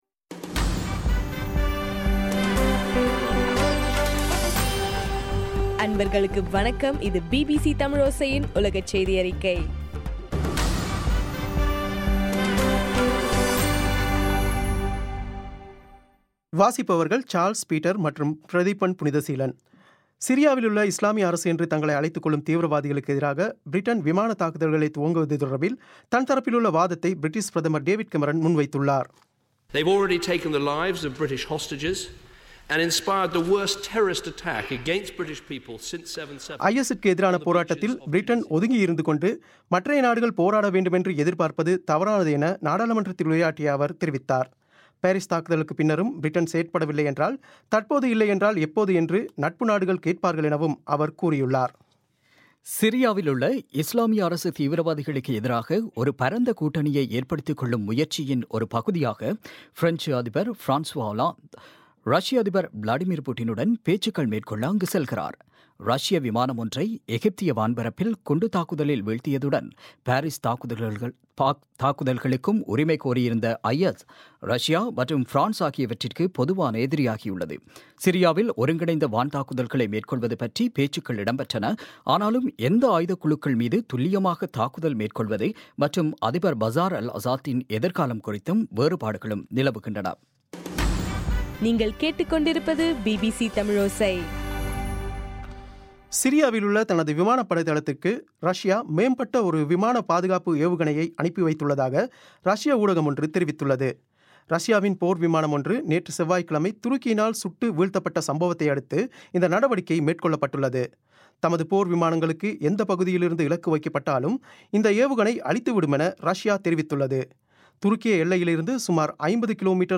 நவம்பர் 26 பிபிசியின் உலகச் செய்திகள்